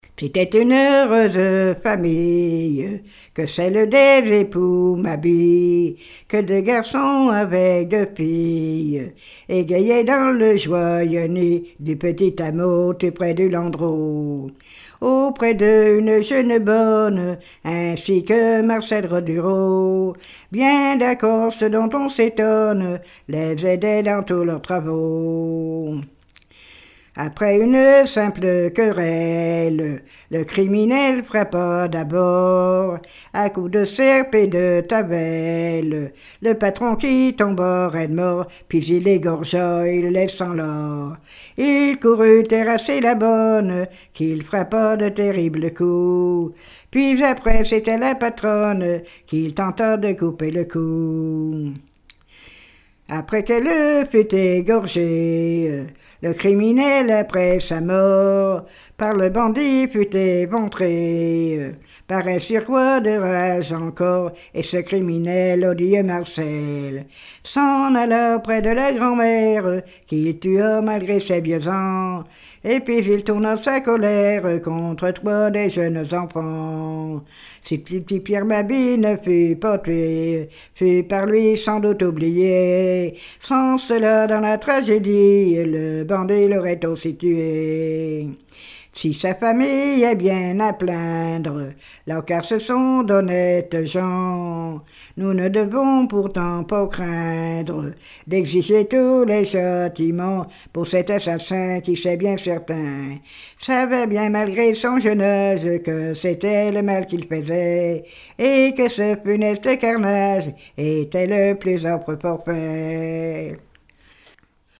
répertoire de chansons populaires
Pièce musicale inédite